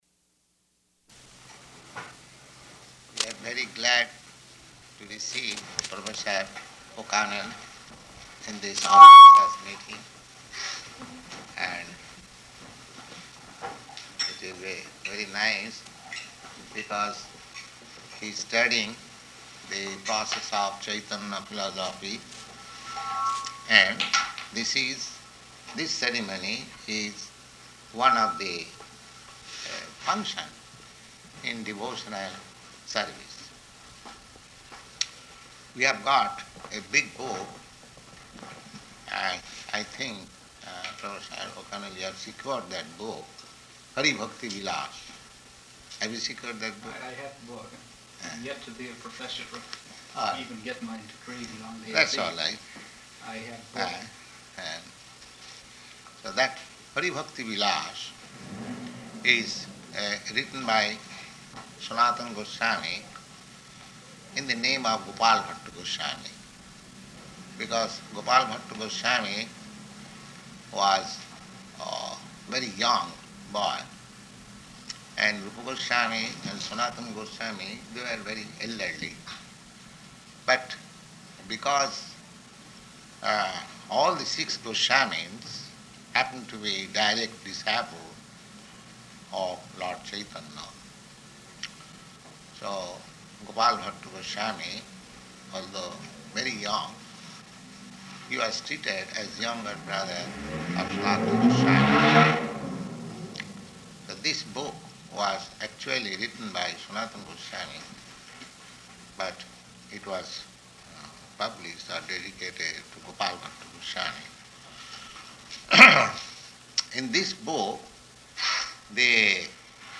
-- Type: Initiation Dated: May 6th 1968 Location: Boston Audio file
[Glenville Ave. Temple]